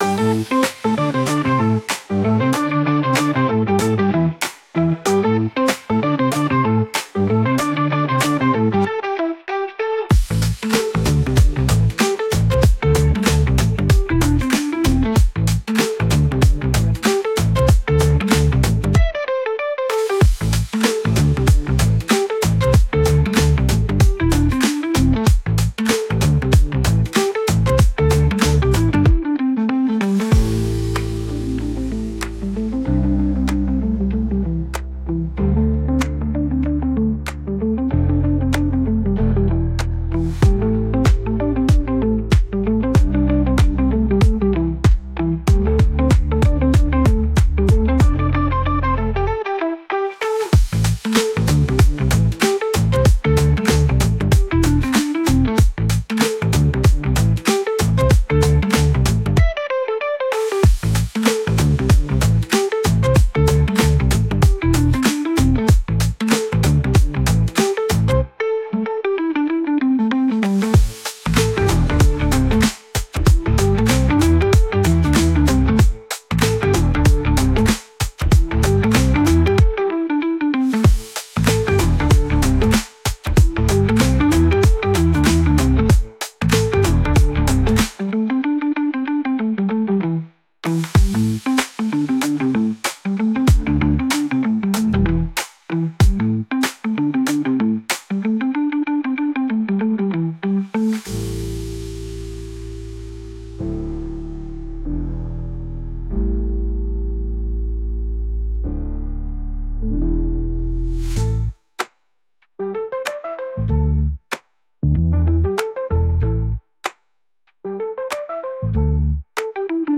Funk
Groovy
96 BPM